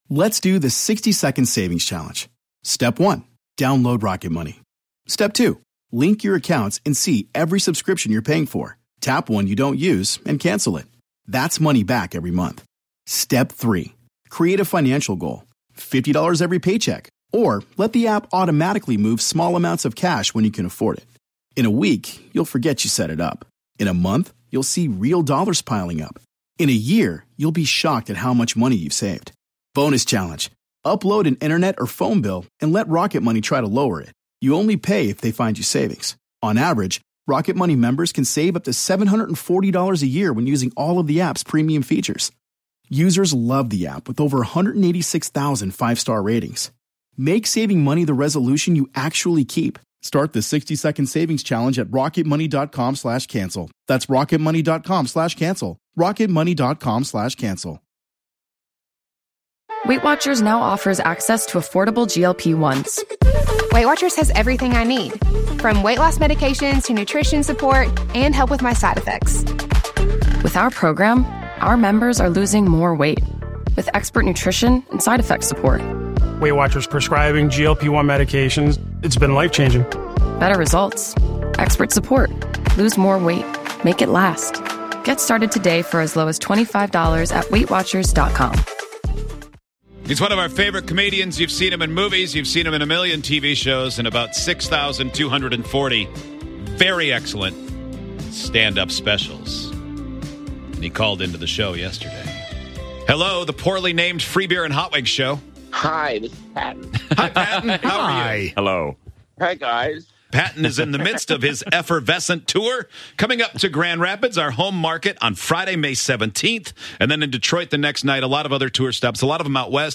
Our Segment 17 Interview With Patton Oswalt
On today's show, Patton Oswalt called in during Segment 17 and we talked all things comedy, tv, and his impressive career.